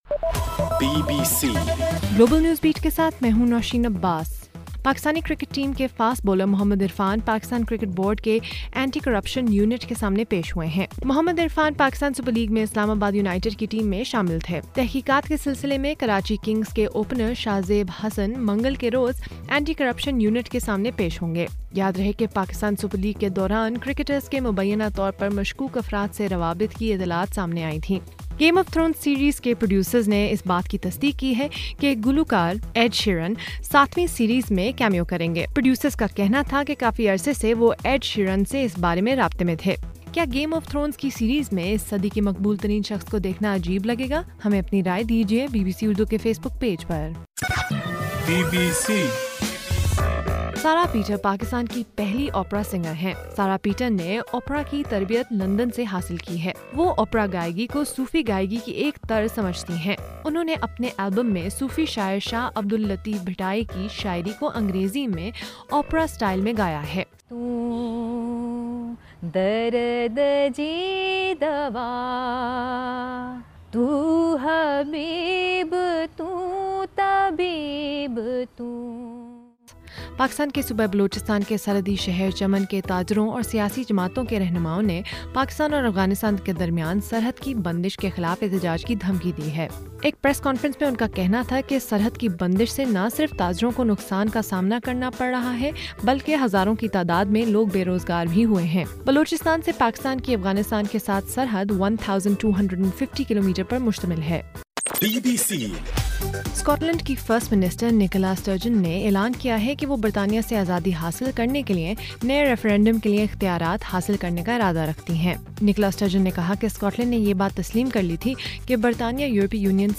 گلوبل نیوز بیٹ بُلیٹن اُردو زبان میں رات 8 بجے سے صبح 1 بجے تک ہر گھنٹے کے بعد اپنا اور آواز ایف ایم ریڈیو سٹیشن کے علاوہ ٹوئٹر، فیس بُک اور آڈیو بوم پر ضرور سنیے۔